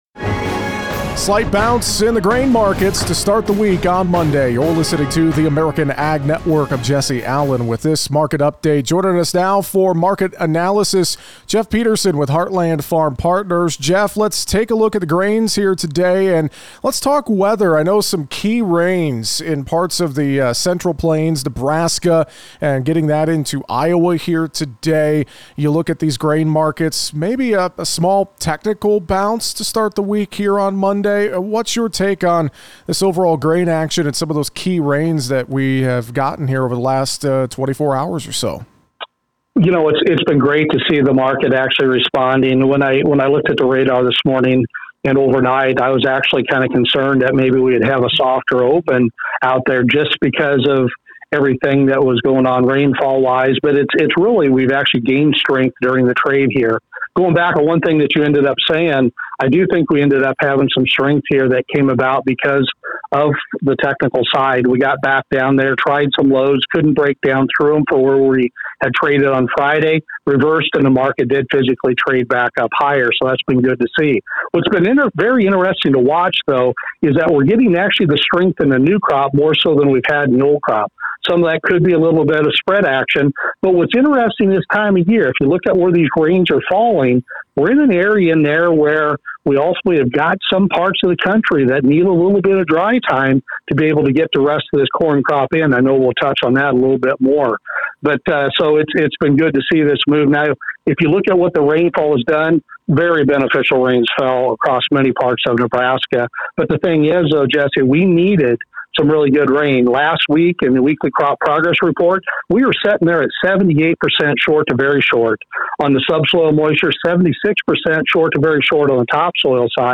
analysis and a conversation about the weather, demand in grains and much more.